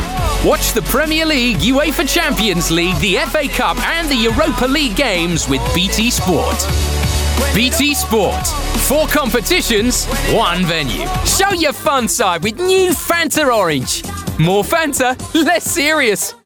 Upbeat Reel
RP ('Received Pronunciation')
Commercial, Bright, Upbeat, Energetic